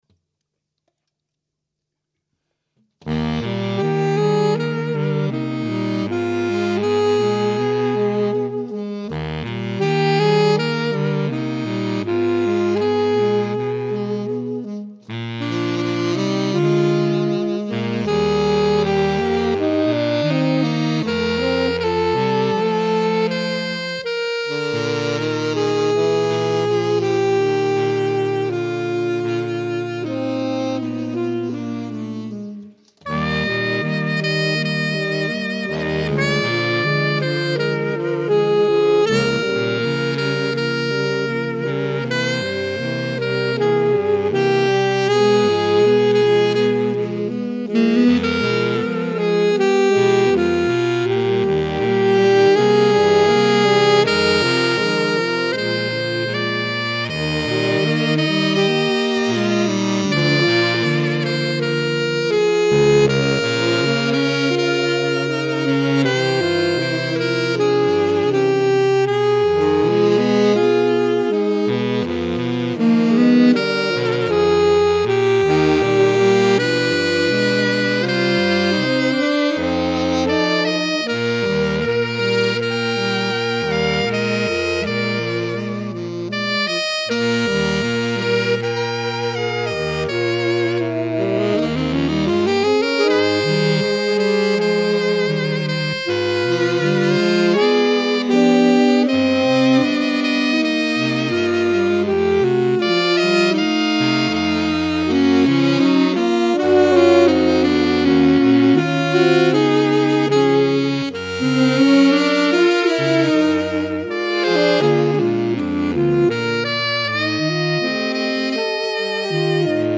romantically style, quasi-Classical composition
Ranges: Alto 1: Bb3. Tenor: D3. Baritone: F1
More Saxophone Quartet Music